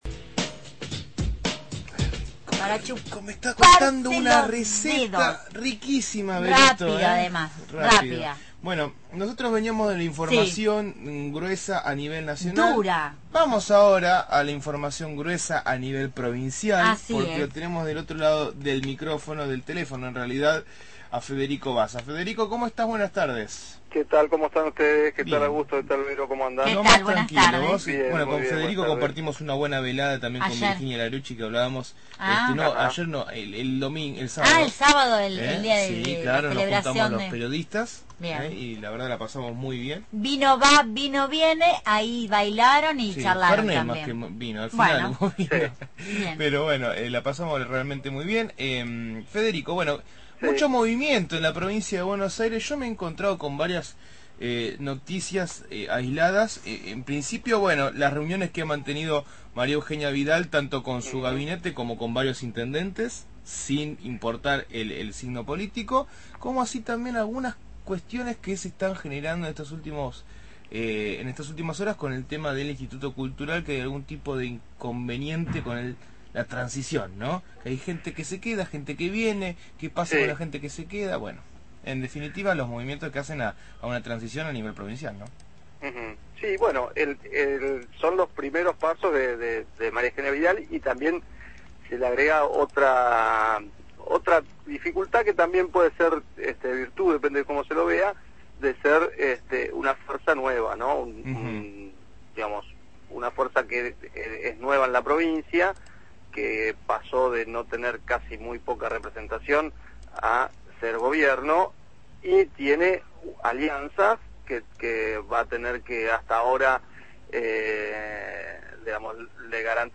habitual columna sobre política provincial